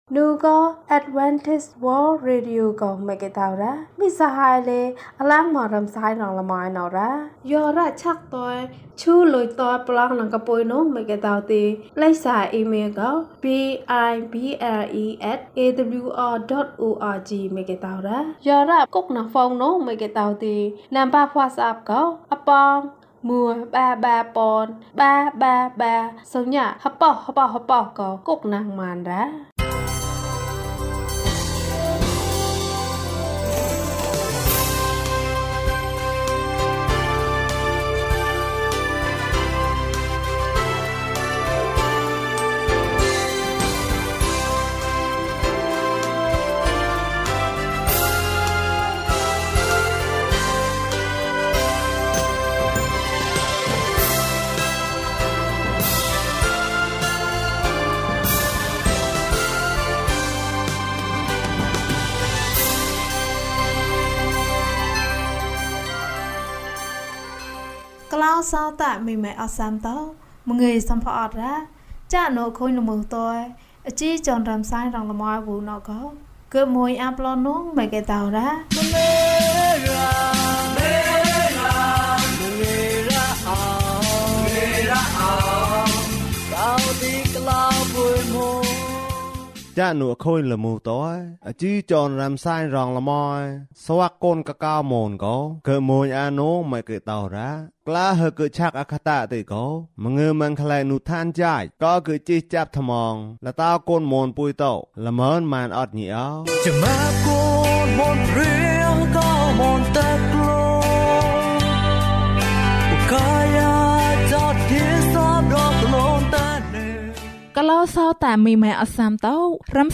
အံ့သြဖွယ်ဘုရားသခင်။၀၂ ကျန်းမာခြင်းအကြောင်းအရာ။ ဓမ္မသီချင်း။ တရားဒေသနာ။